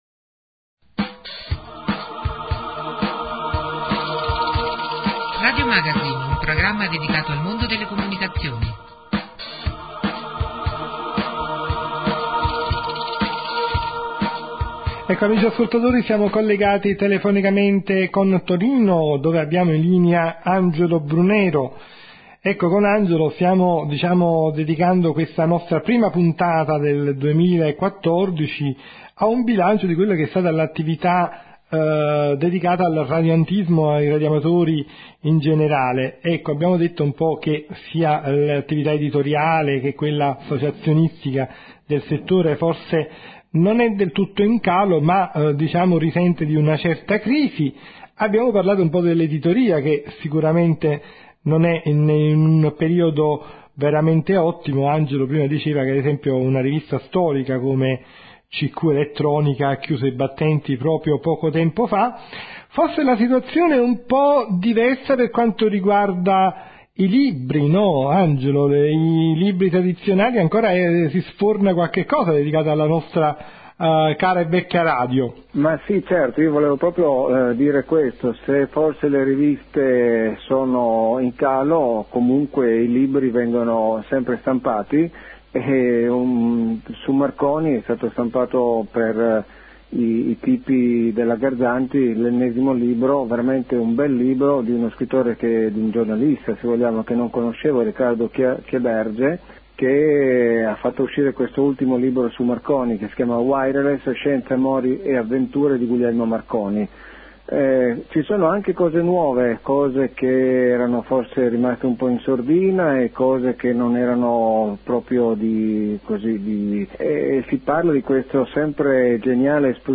qualità 64 kbps/44 kHz/mono (circa 10 M)